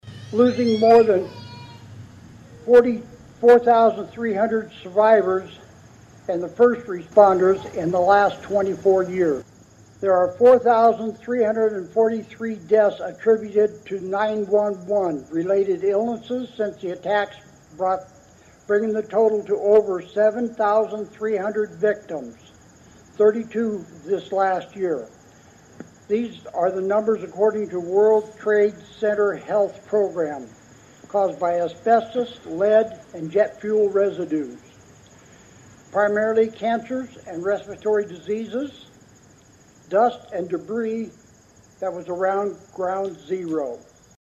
(Atlantic) The American Legion Post #43 held a Remembrance Day ceremony Thursday morning at the Atlantic City Park to honor first responders for their heroic efforts during the current day and the September 11, 2001, attacks on the United States.